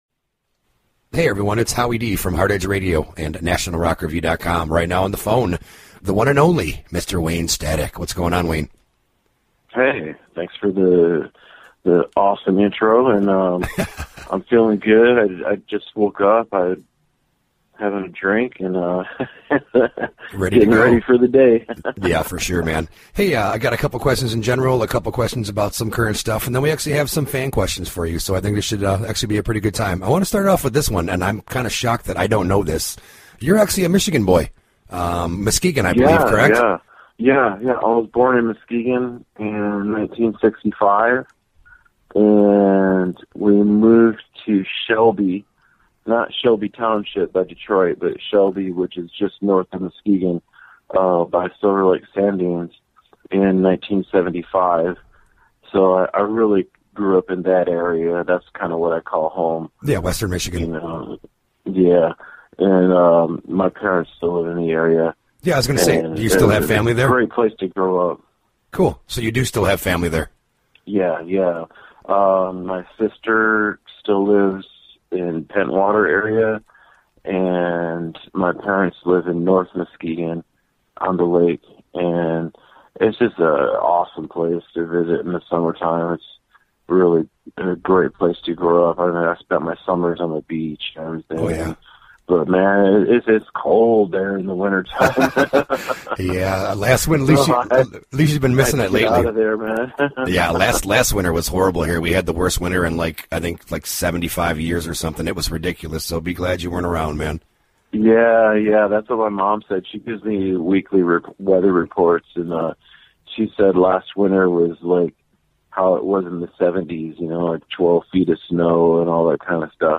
In Memoriam: Wayne Static (Nov 04, 1965 - Nov 01, 2014) w/Final Interview - National Rock Review